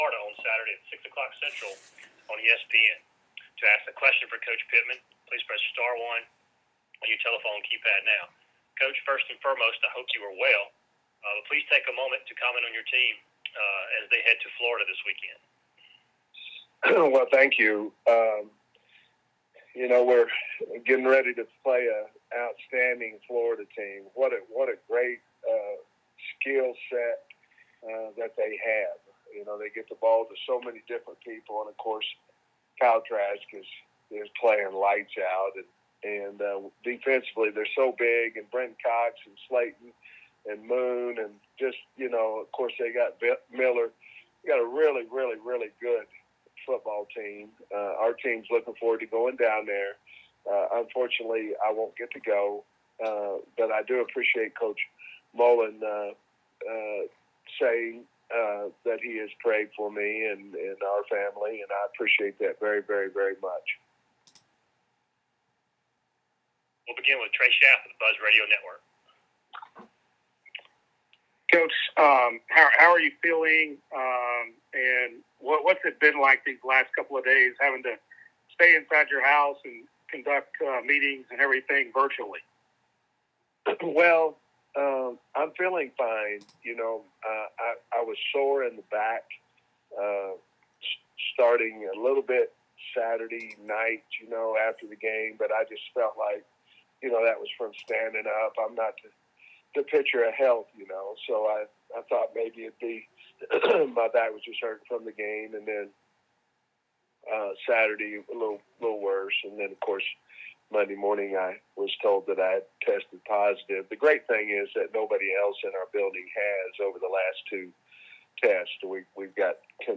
Sam Pittman on the SEC teleconference - Week 8